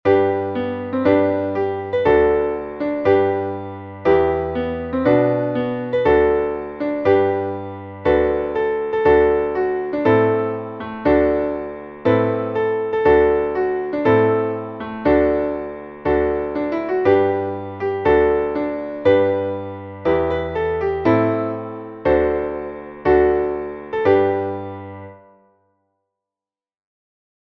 Traditionelles Volks-/ Trinklied